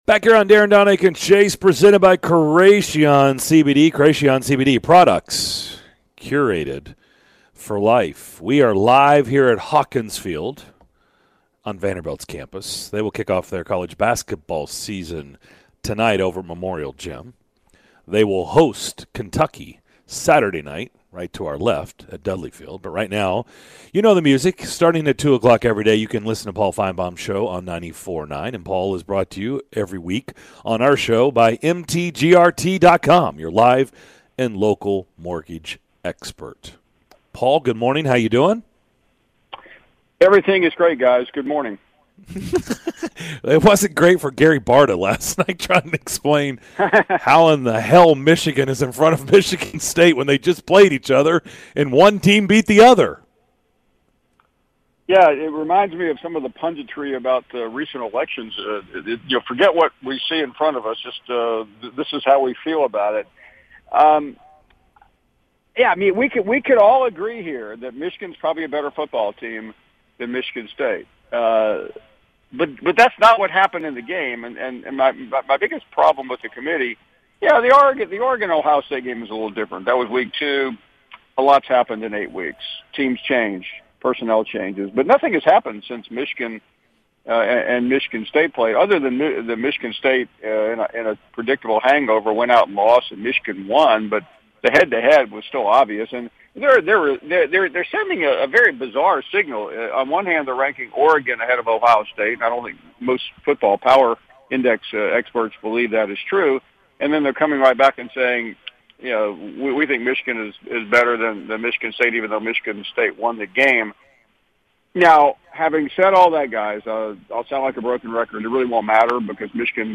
ESPN's Paul Finebaum joined the DDC to give his thoughts on the latest CFP rankings, Dan Mullen's nightmarish season at Florida and more during his weekly visit!